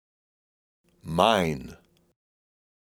Word: Mine (Male Voice)
The word "Mine" spoken by a male voice
Recording Location: (In Studio)